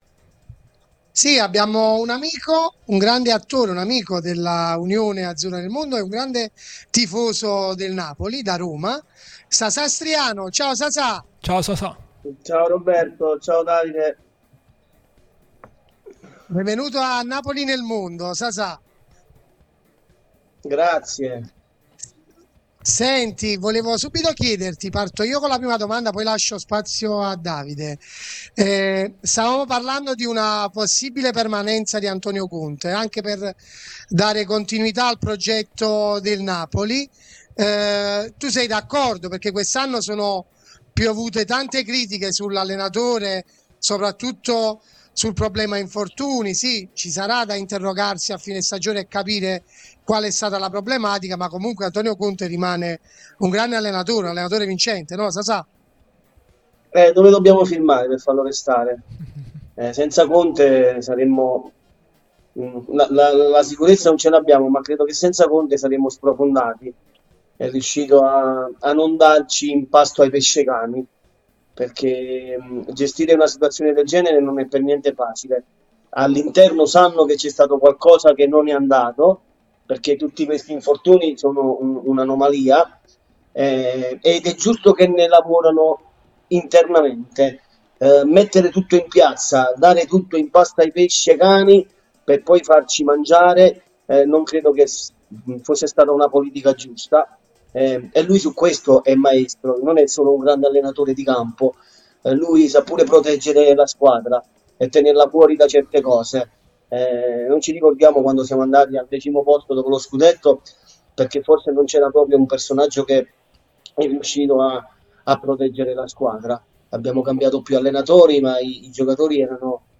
L'attore napoletano Sasà Striano è intervenuto su Radio Tutto Napoli